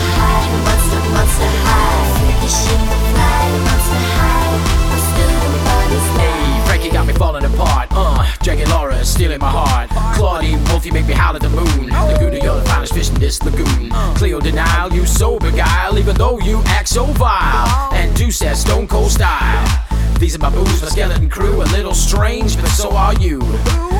Duet Version